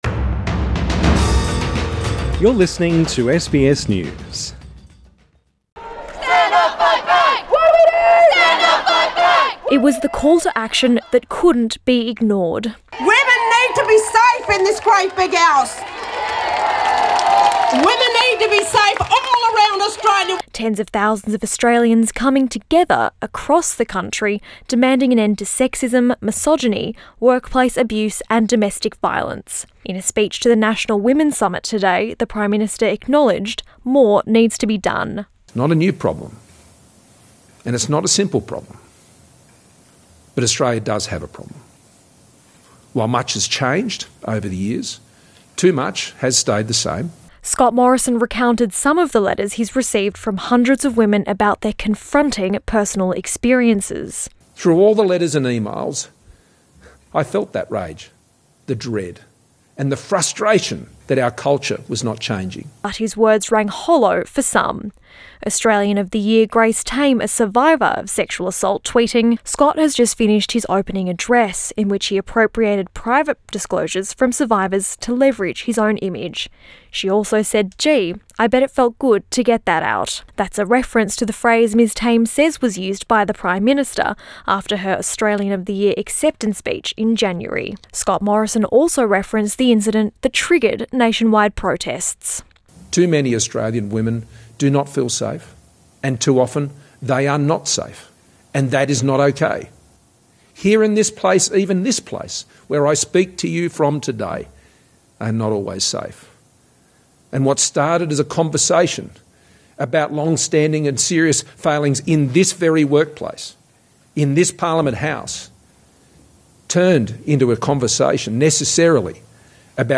Scott Morrison delivers a keynote speech via video link during the National Summit on Women’s Safety Source: AAP